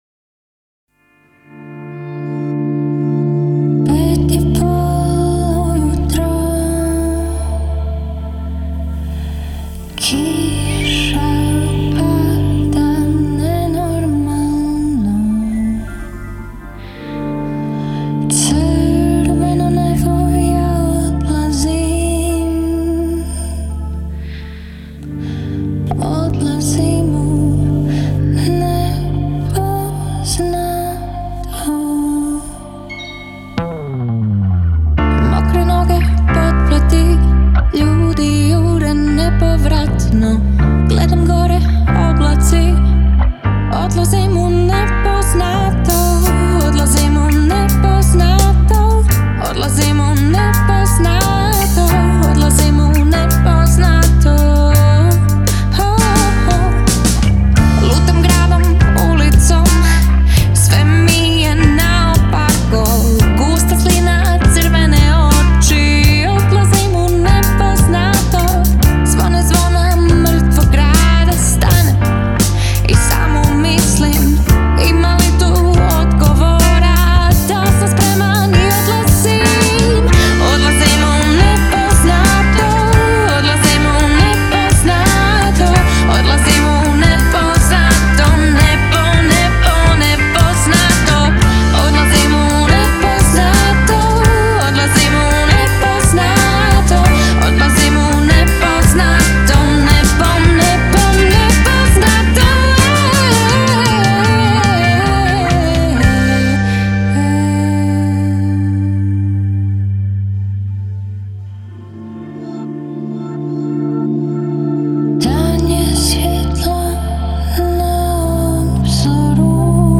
pop-rock-funk-punk bend